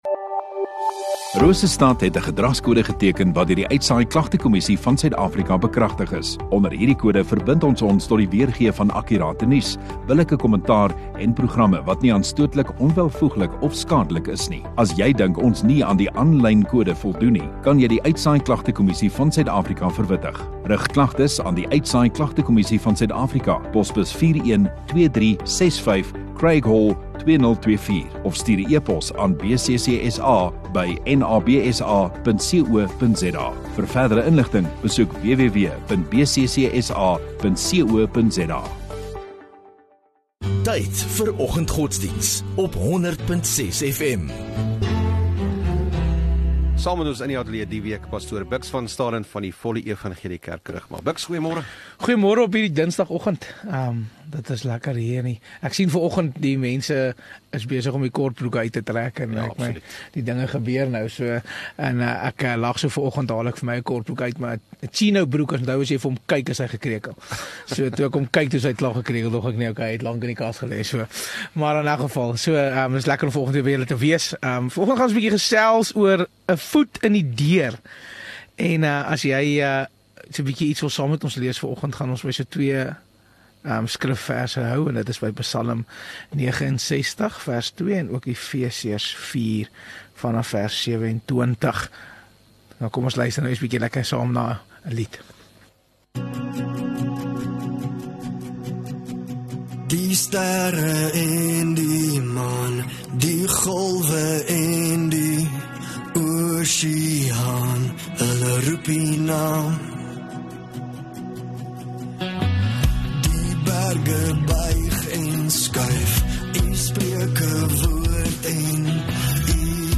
8 Oct Dinsdag Oggenddiens